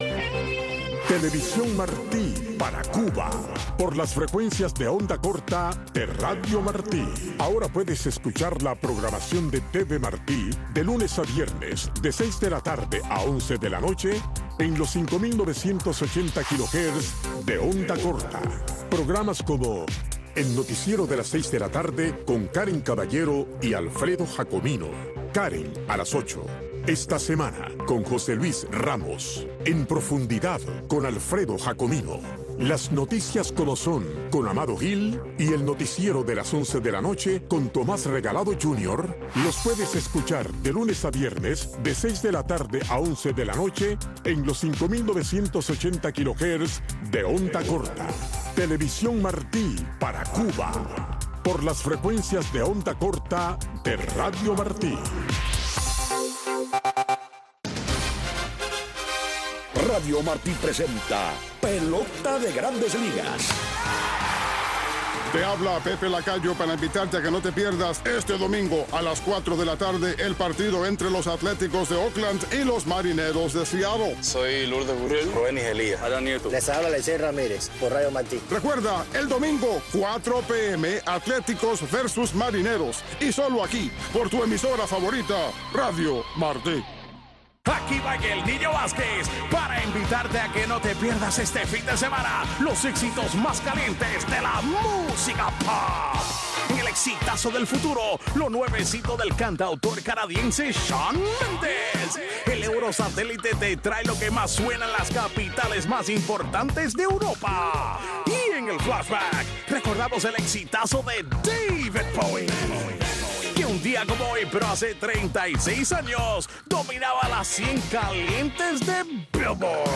Soy Guajiro es un programa para los campesinos y guajiros con entrevistas, música y mucho más.